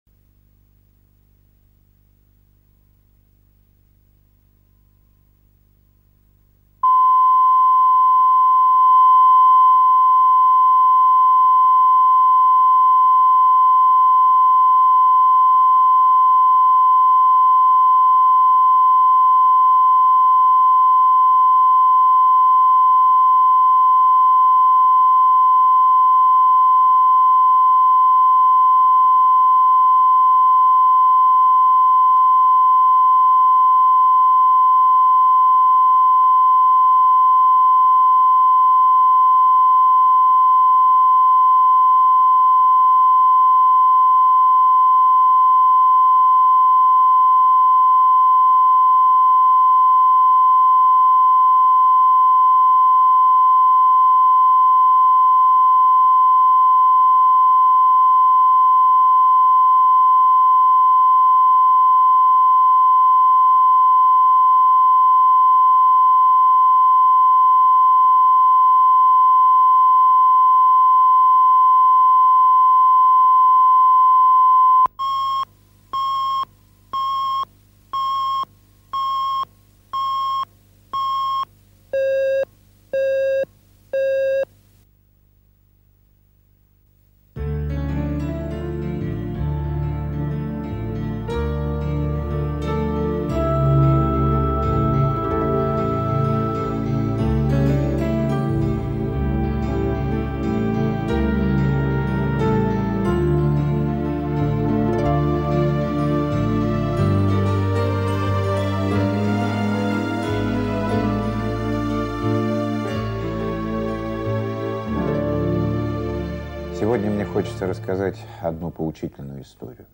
Аудиокнига Санта Папас